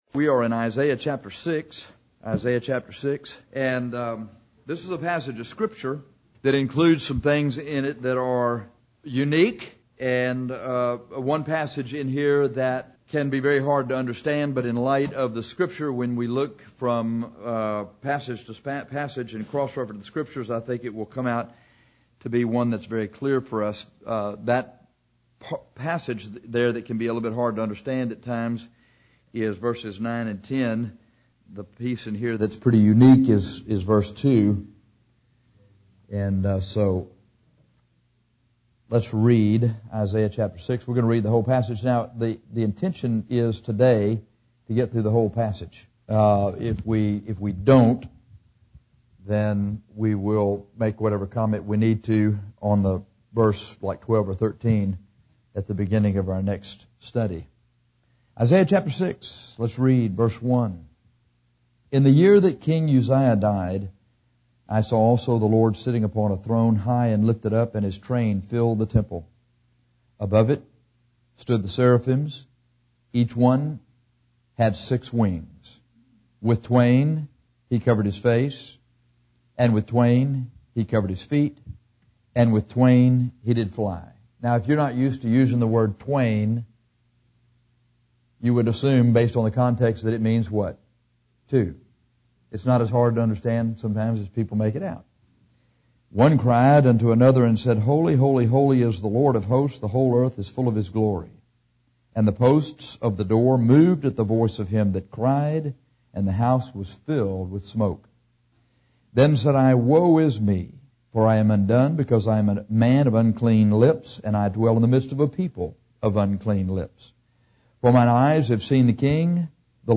Here is a good outline of this portion of Isaiah 6 along with some general comments to go along with the audio recording of this lesson.